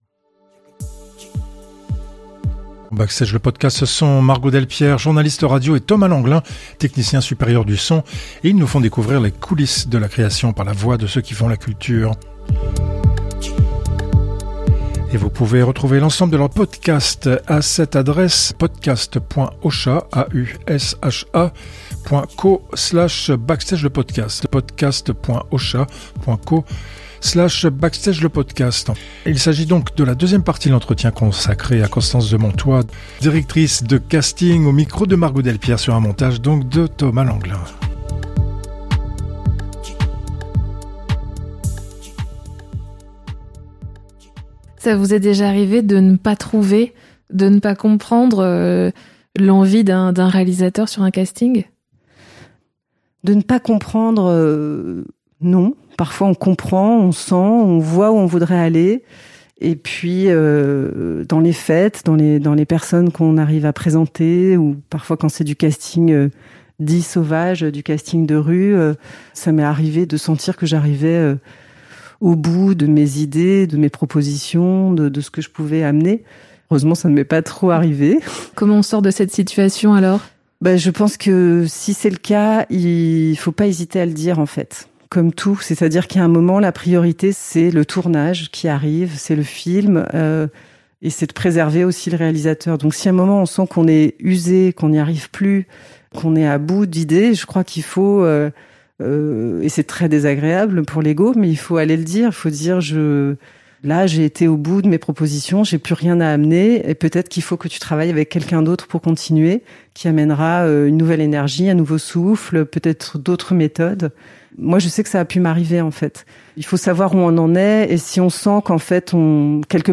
Entretien enregistré en novembre 2020.